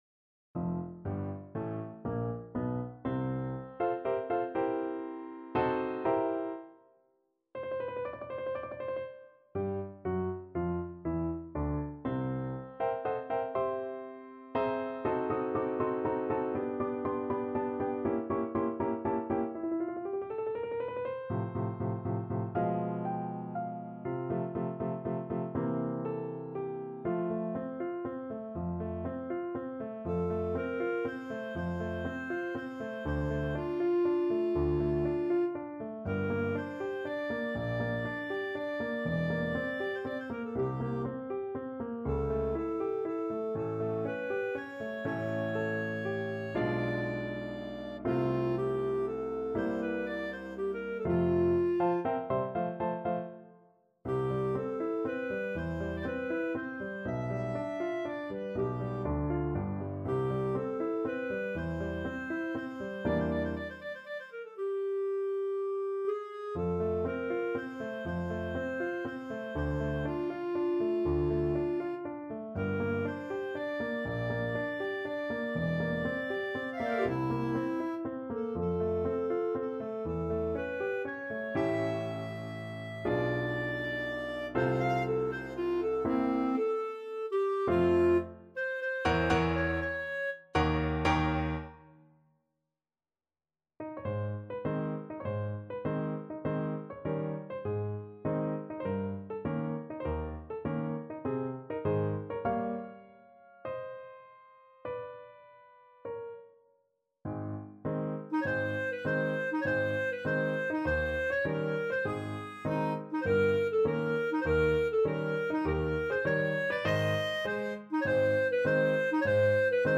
Play (or use space bar on your keyboard) Pause Music Playalong - Piano Accompaniment Playalong Band Accompaniment not yet available transpose reset tempo print settings full screen
Clarinet
6/8 (View more 6/8 Music)
Andante =120
F major (Sounding Pitch) G major (Clarinet in Bb) (View more F major Music for Clarinet )
Classical (View more Classical Clarinet Music)